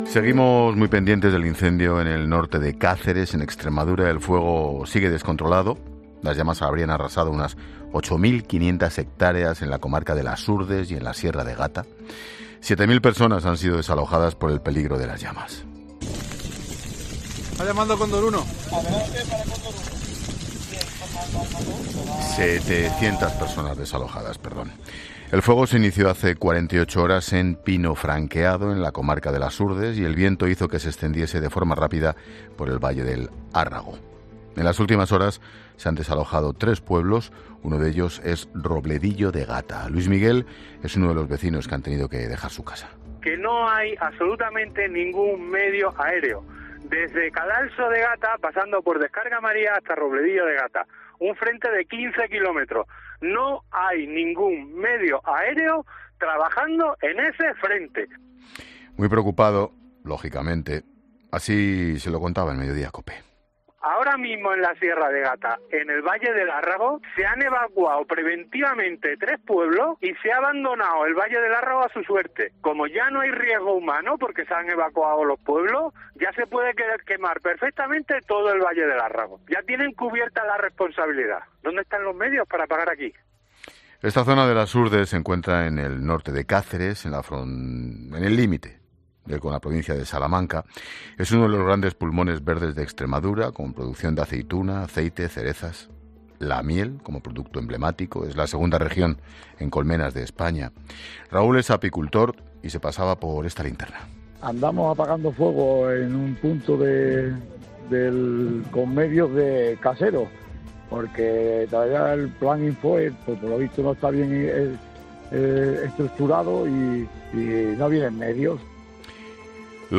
El regidor del municipio extremeño explica en La Linterna que las rachas de viento continuarán hasta el domingo: "Este sábado estaremos igual"
Así, el alcalde de Pinofranquado, José Luis Azabal, ha pasado por los micrófonos de La Linterna para explicar que esperan que las rachas de viento continúen, al menos, hasta el domingo: “Este sábado va a ser igual, dependemos del viento y la cosa pinta mal todavía”, explica a Ángel Expósito.